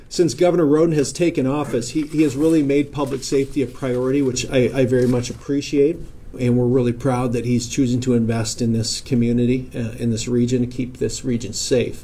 At the announcement, Governor Rhoden was joined by state, federal, city, and county law enforcement leaders including Sioux Falls Mayor Paul TenHaken.